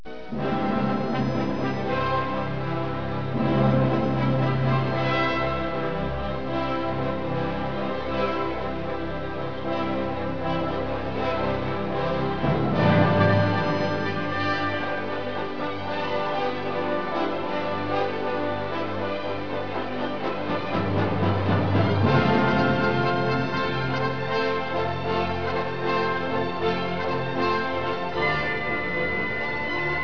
Caccia nel bosco: mentre il fiume scorre nella foresta boema ecco risuonare i corni dei cacciatori.